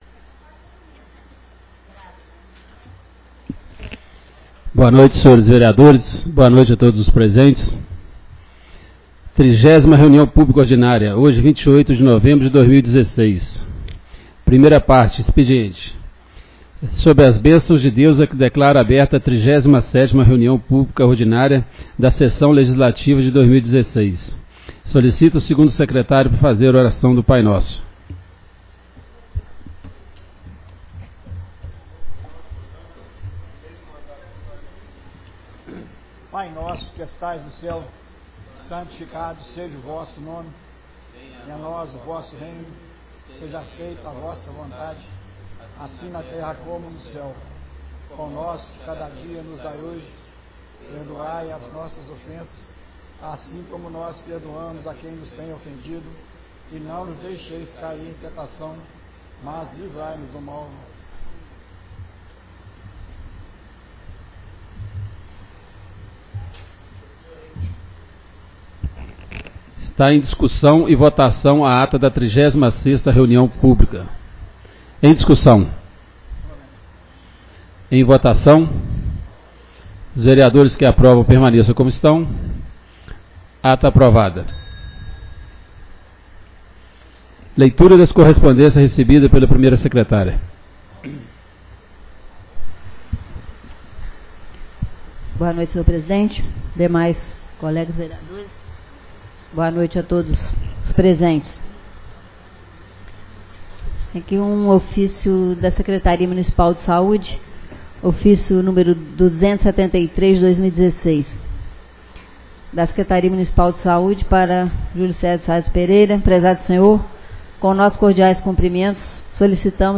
37ª Reunião Pública Ordinária